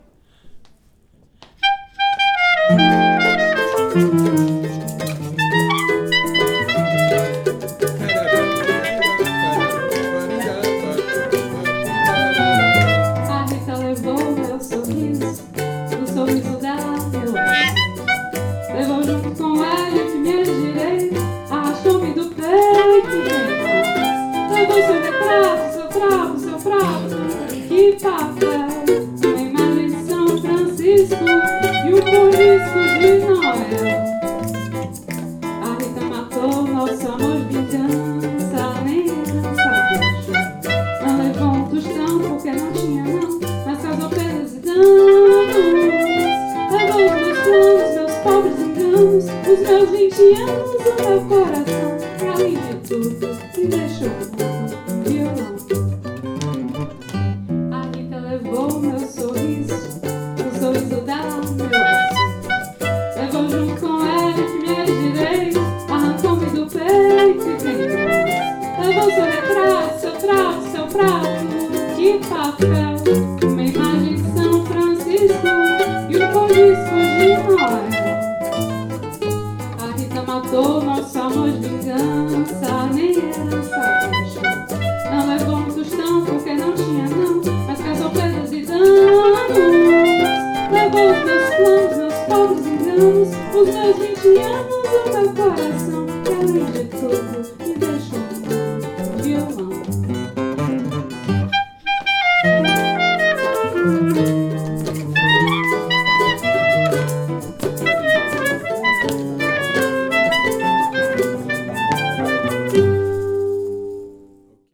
Rec atelier
Session sans percussions
A_Rita_sans_percus.mp3